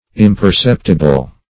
Imperceptible \Im`per*cep"ti*ble\, a. [Pref. im- not +